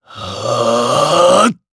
Lusikiel-Vox_Casting3_jp.wav